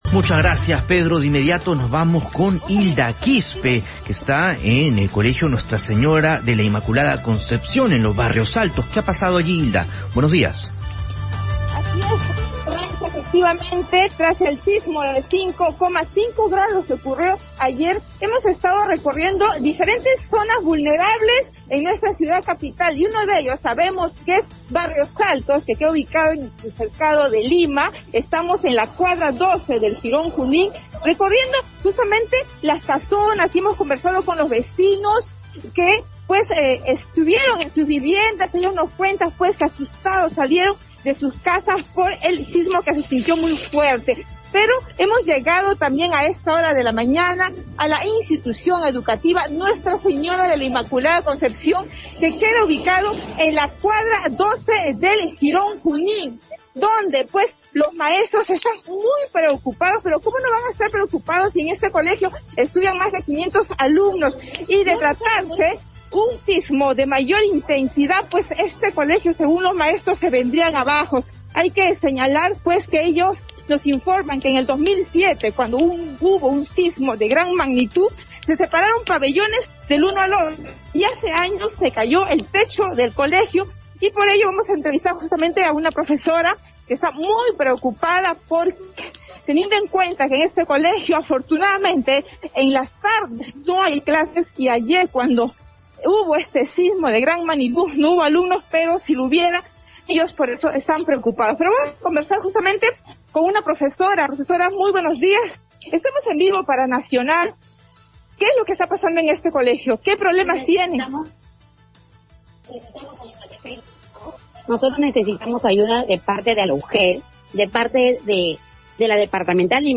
En vivo desde el Cercado de Lima, se informó que los maestros de la I.E. Nuestra señora de la inmaculada concepción están preocupados debido a que ante un sismo el colegio se vendría abajo.